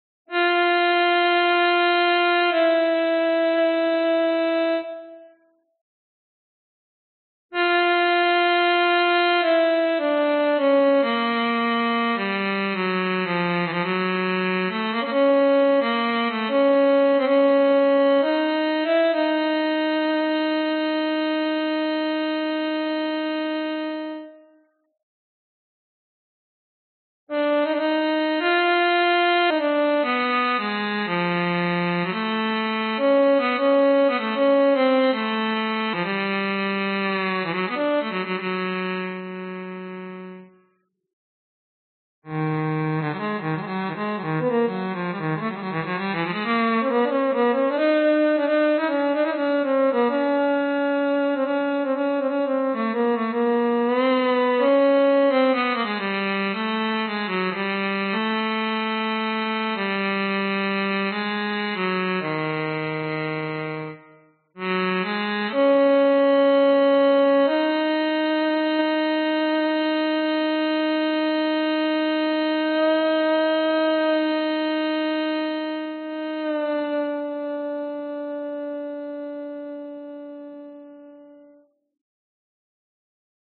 描述：大铜管乐器声
Tag: 120 bpm Orchestral Loops Brass Loops 2.69 MB wav Key : Unknown Reason